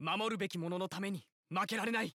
File:Roy voice sample JP.oga
Roy_voice_sample_JP.oga.mp3